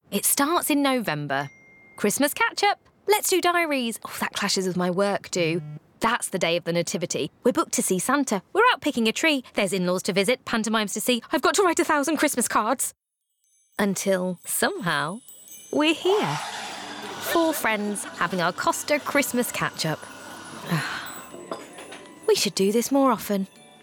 Brummie
Neutral
Female
Bright
Versatile
Warm
COSTA COMMERCIAL